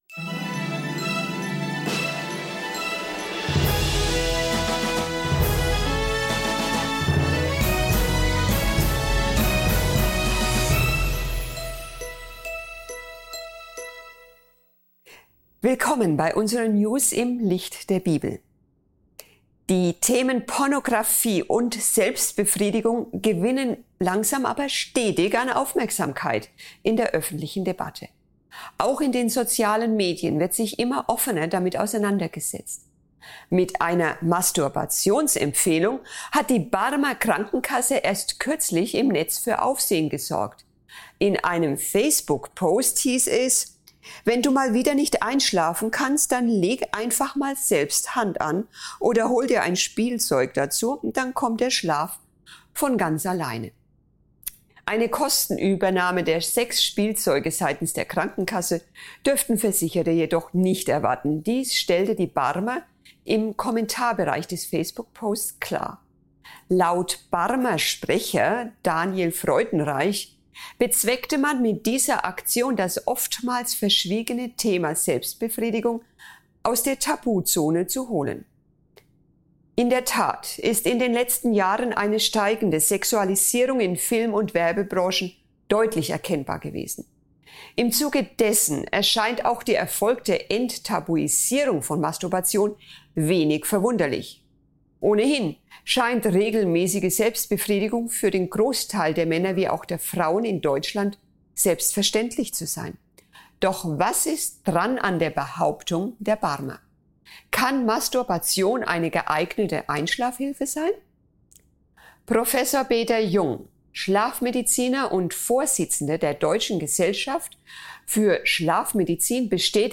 In einem spannenden Vortrag wird die wachsende öffentliche